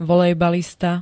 Zvukové nahrávky niektorých slov
z2t2-volejbalista.ogg